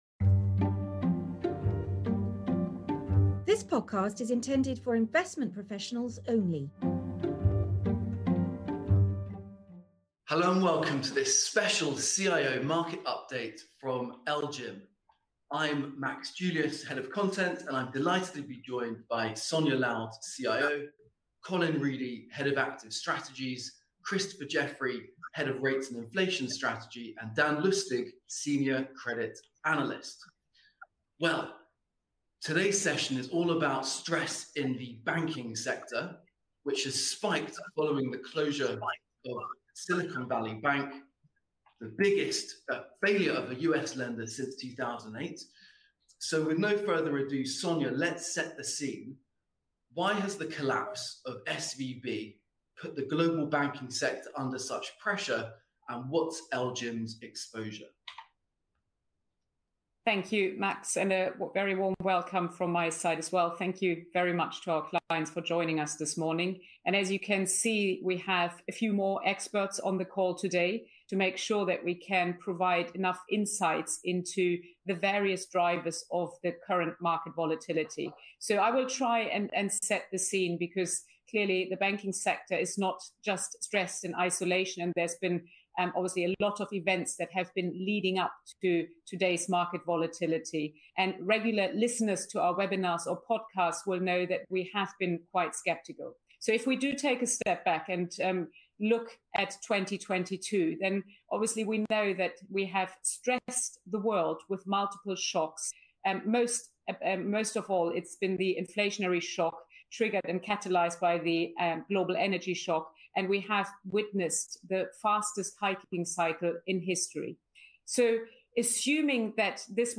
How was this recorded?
We’ve held a special live webinar with our clients to discuss the pressure on the global banking sector – and on European lenders in particular – following the closure of SVB, the biggest US bank failure since 2008.